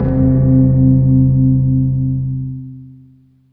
kamikazerespawn.ogg